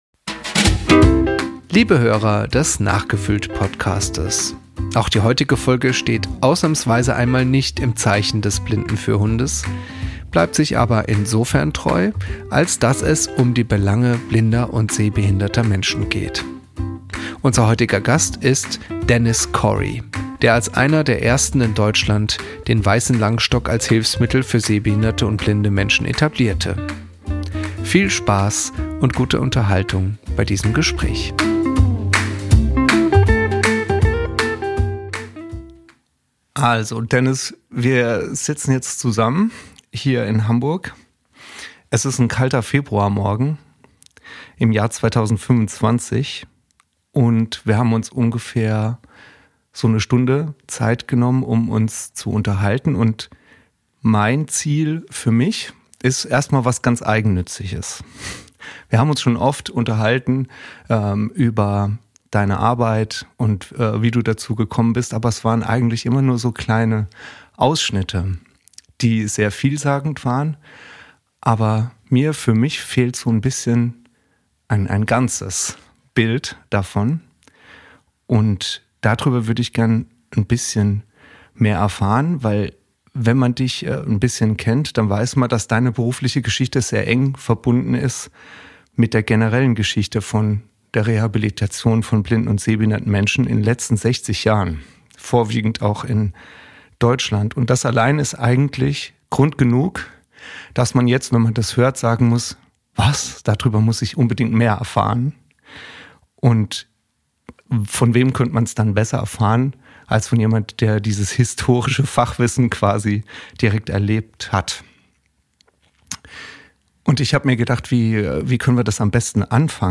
Im Interview